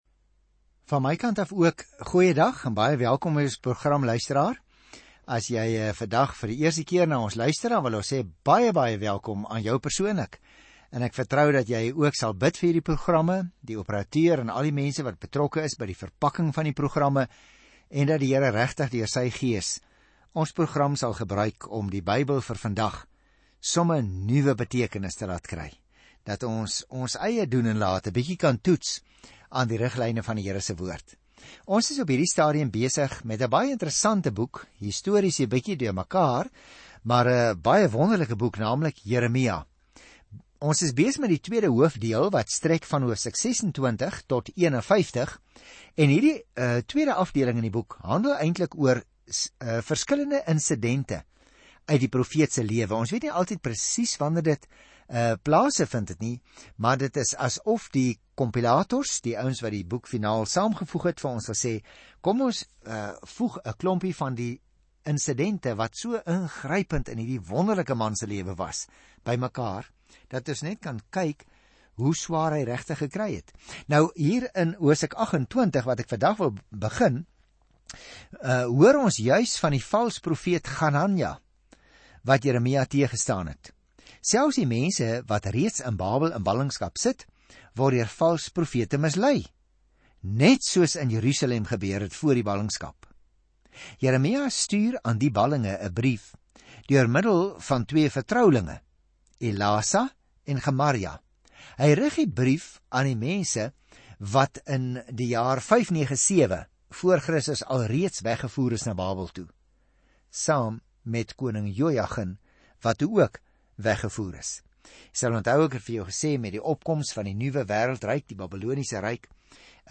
Dit is ’n daaglikse radioprogram van 30 minute wat die luisteraar sistematies deur die hele Bybel neem.